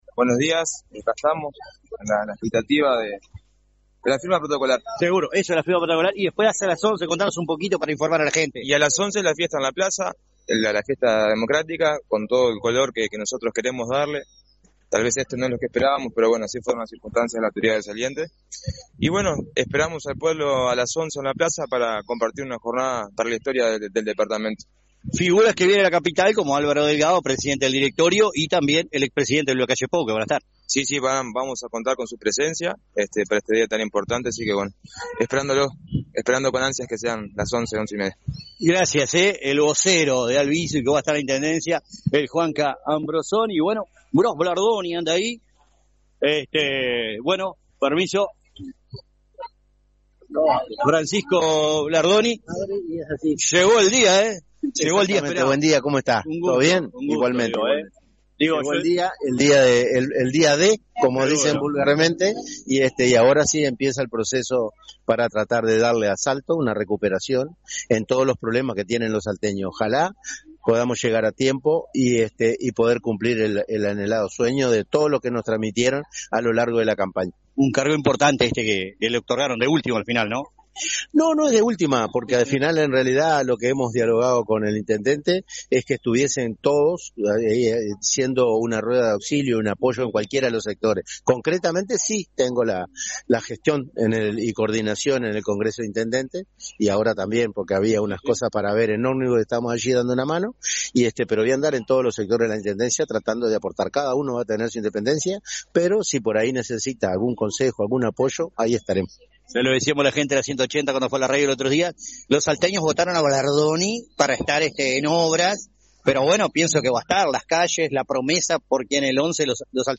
Luego con una plaza colmada primero recibió autoridades que llegaron de la capital, de Entre Ríos (Gobernador) como Gobernador de Río Grande Do Sul.